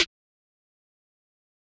Metro Snare 13.wav